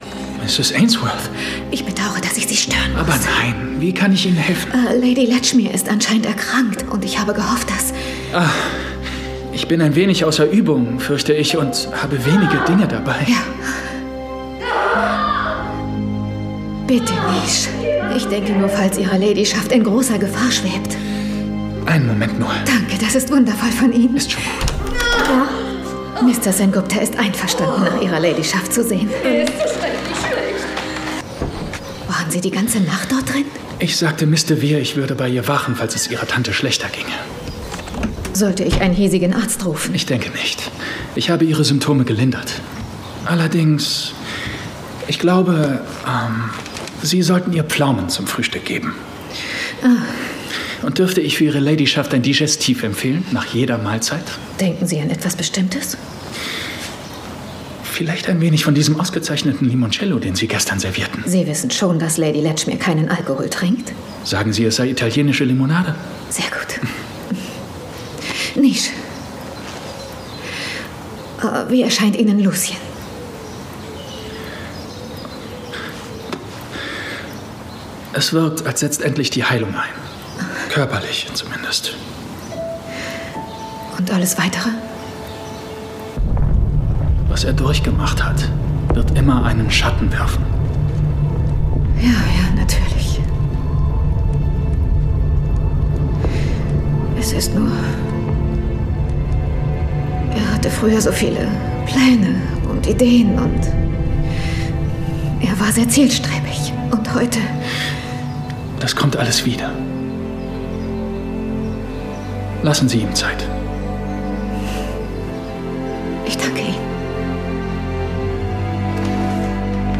Synchronausschnitt